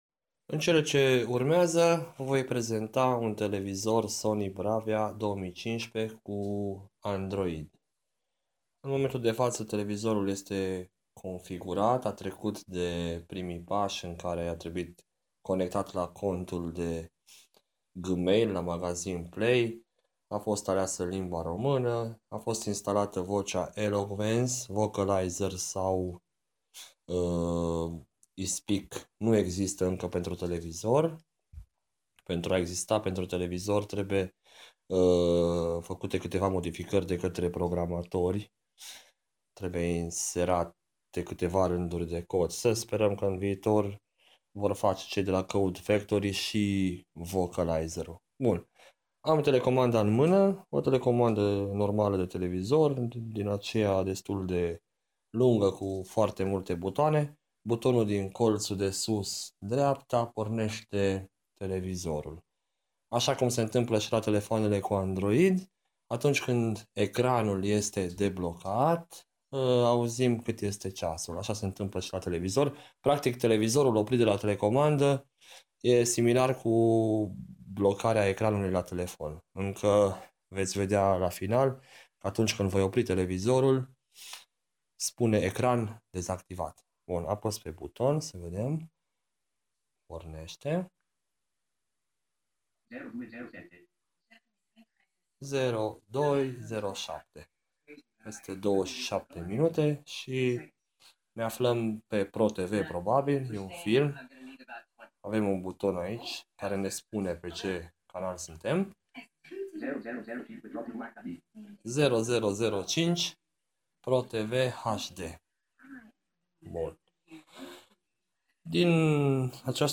În cele din urmă am făcut o mică prezentare, din păcate se aude cam încet vocea Eloquence cu italiana, până la urmă tot târziu m-am apucat, pe la 02:07 după cum se va auzi la pornirea televizorului.